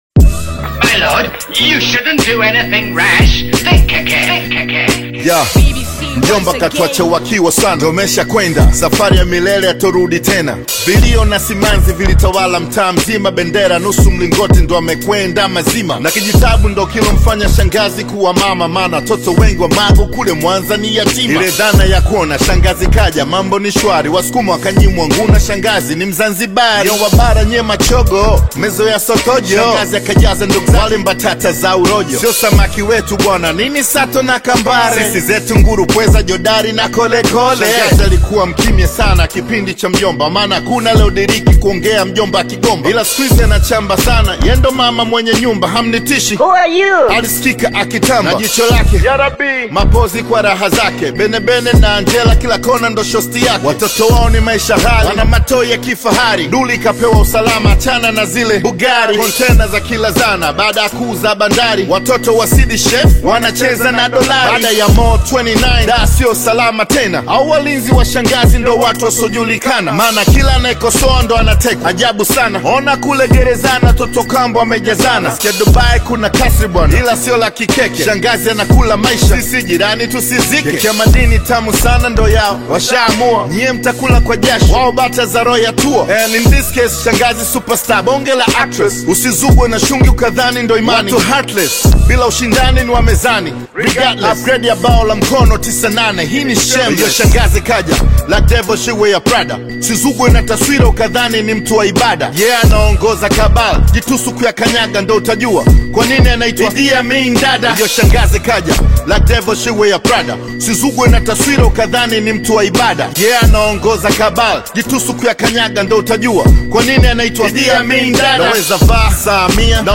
is an energetic Tanzanian Hip Hop single
Driven by rapid Singeli beats and playful Swahili lyrics